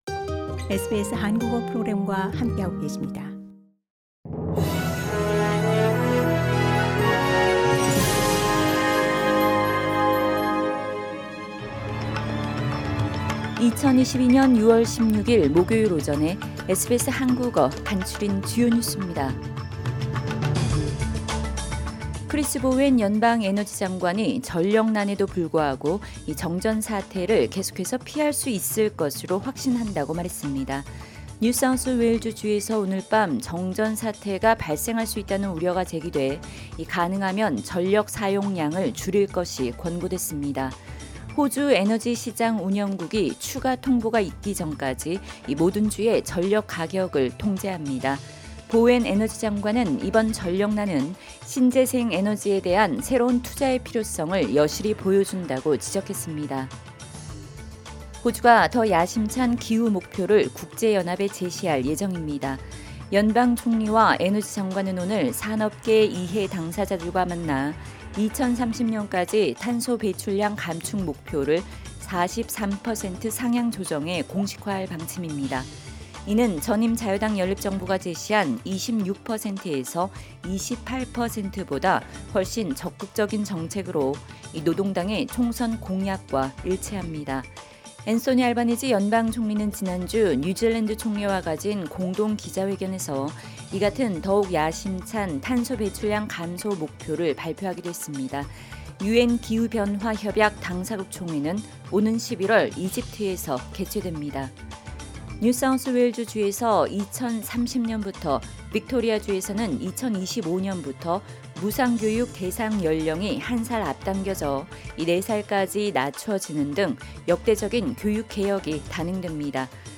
SBS 한국어 아침 뉴스: 2022년 6월 16일 목요일